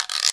Guiro.wav